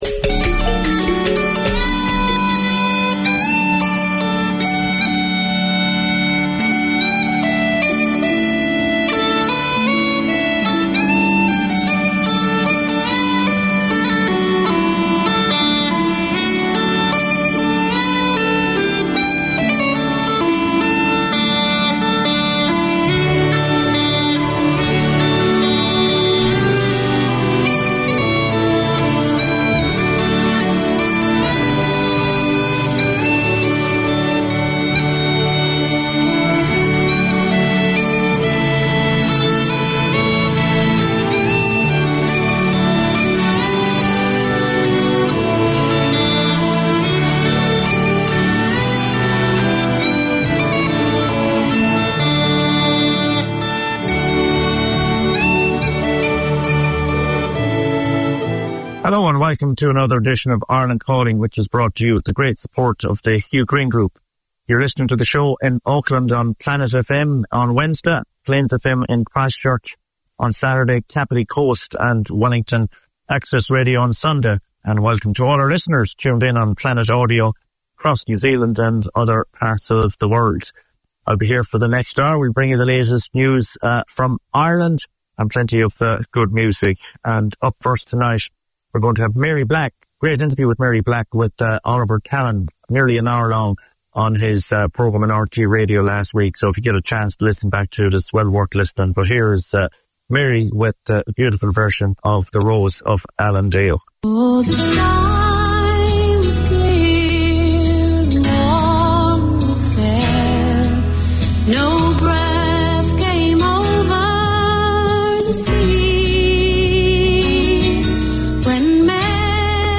Featuring a wide range of Irish music and the occasional guest, including visiting Irish performers, politicians, sports and business people.